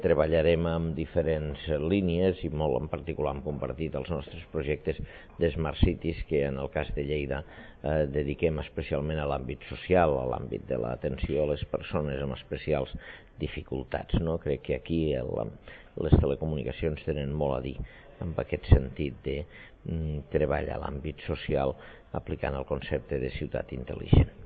Tall de veu de l'alcalde de Lleida, Àngel Ros
tall-de-veu-de-lalcalde-de-lleida-angel-ros